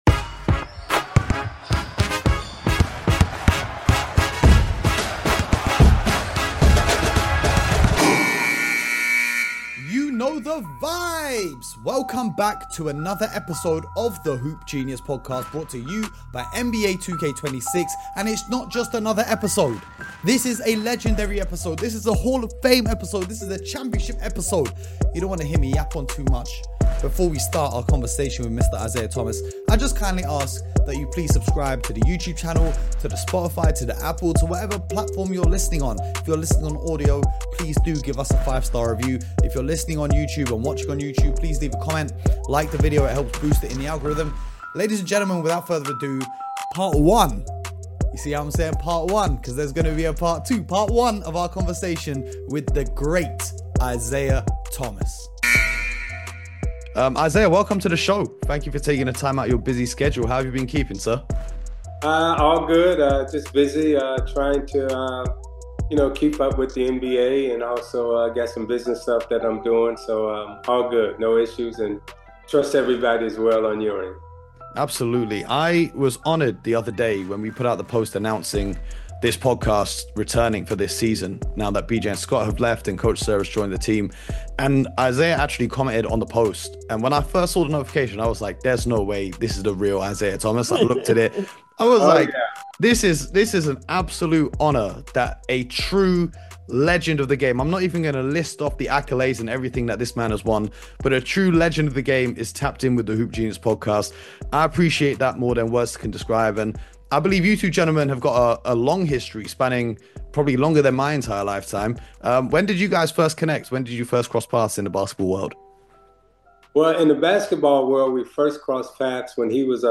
NBA legend Isiah Thomas joins the show for an unfiltered interview about his career, his rivalries, and his thoughts on today’s NBA.